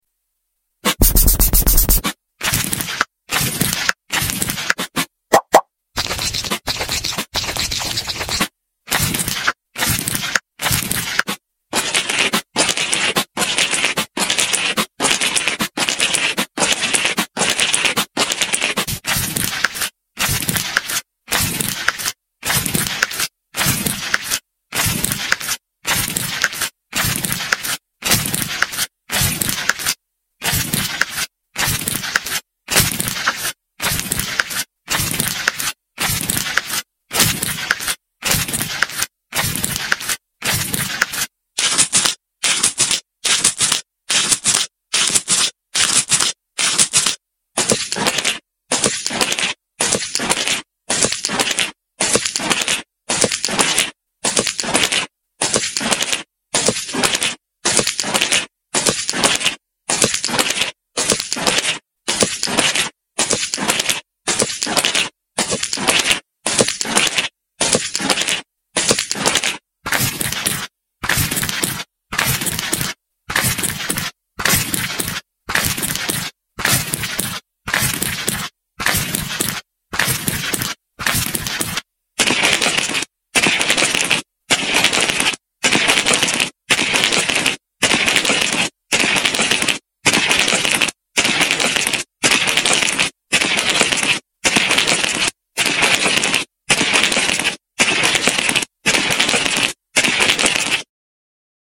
foot spa & pampering ASMR| sound effects free download
foot spa & pampering ASMR| soft sounds for stress_Free sleep